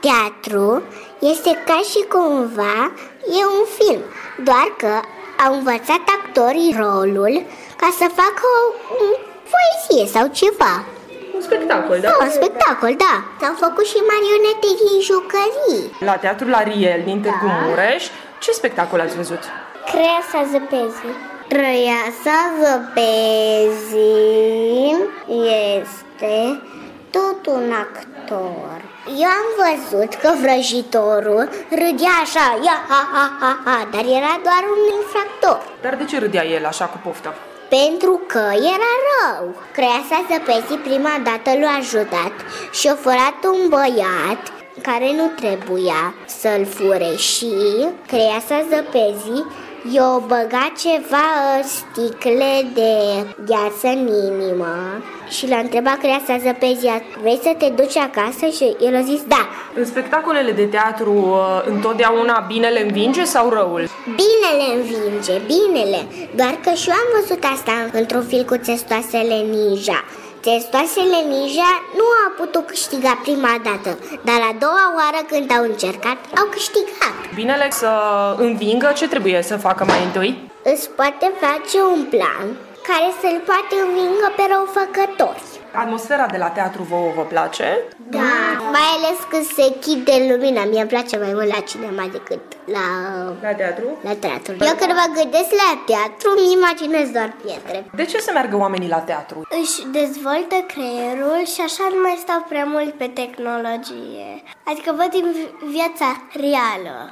„Teatrul e ca un film, doar că actorii și-au învățat rolul”, spun copiii care sărbătoresc ziua mondială a teatrului, vizionând spectacolul „Crăiasa zăpezii”, la Teatrul Ariel din Târgu Mureș. Binele învinge, dar nu din prima, trebuie să aibă un plan, mai spun copiii: